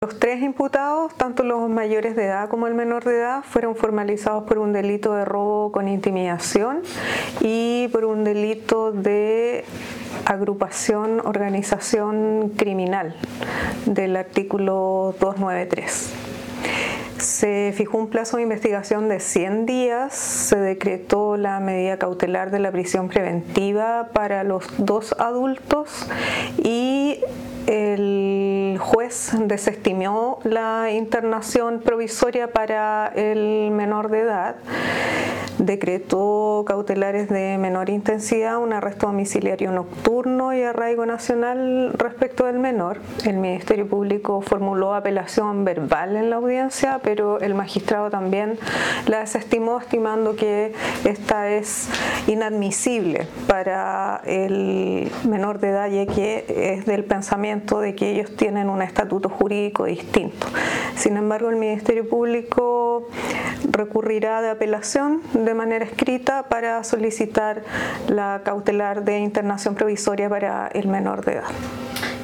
La fiscal SACFI Valparaíso, Lidia Aspee, entregó detalles al respecto:
fiscal-SACFI-Valparaiso-Lidia-Aspee.mp3